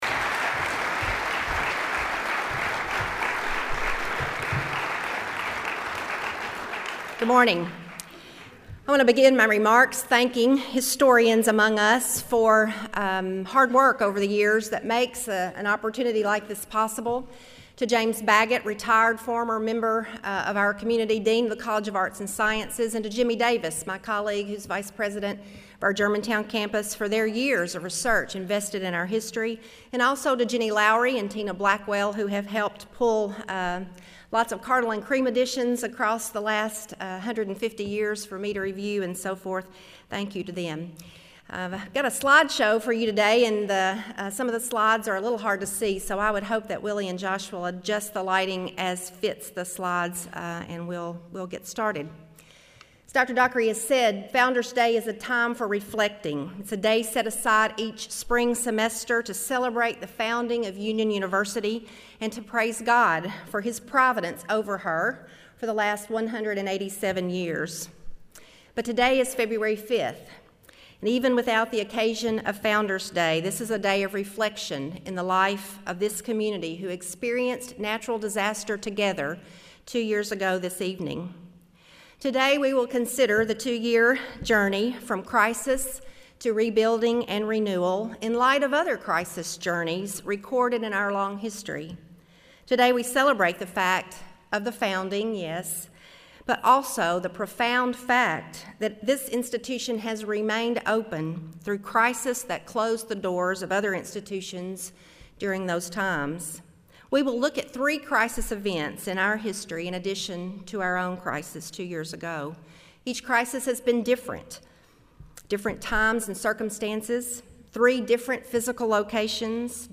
Founders Day Chapel